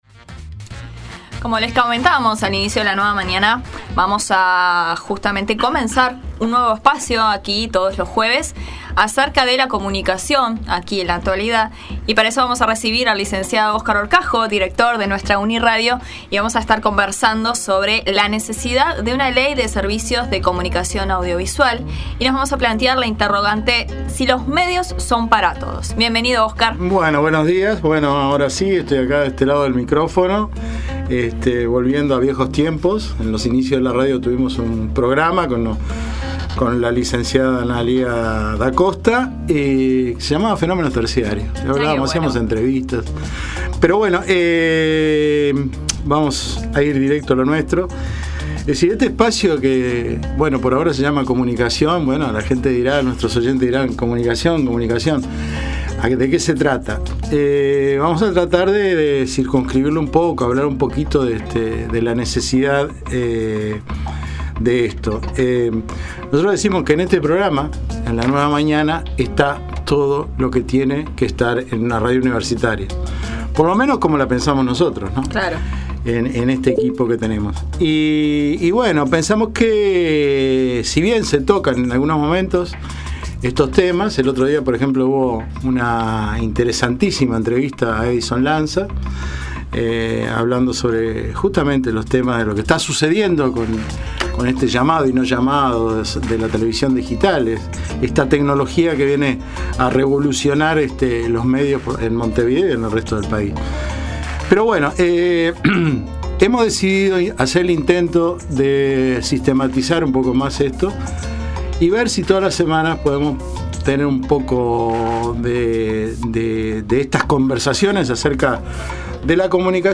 Recibimos en los estudios de UNI Radio